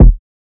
Princess Kick
Princess-Kick.wav